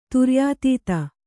♪ turyātīta